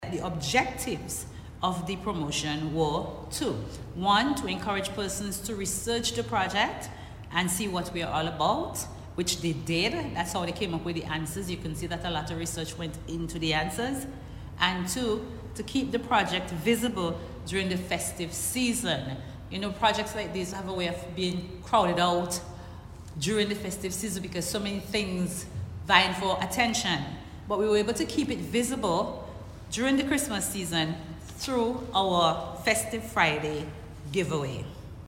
Speaking at Wednesday’s Prize Giving Ceremony